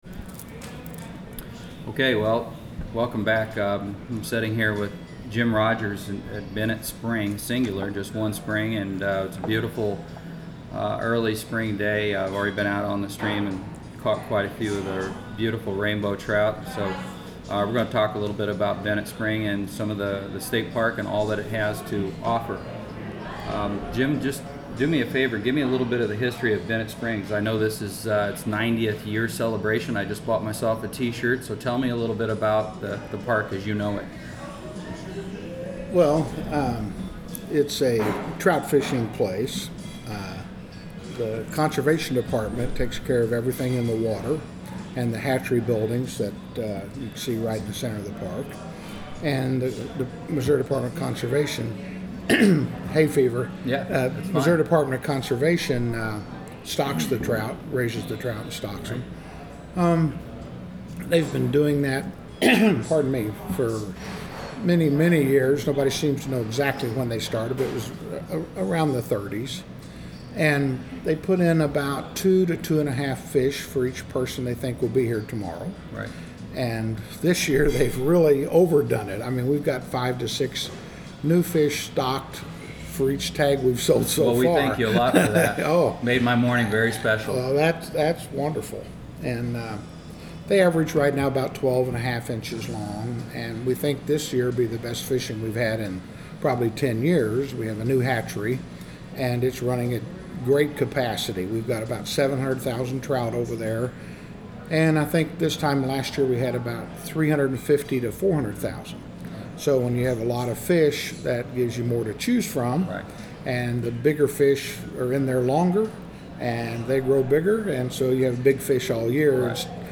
The interview is a bit over 11 minutes and I feel confident that our discussion of the park, its fishing, the rearing of trout, the fly fishing school and other topics will be a very interesting listen.